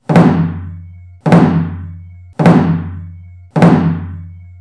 Trống Chiến
Tà rùng:  Ví dụ: (386-009m)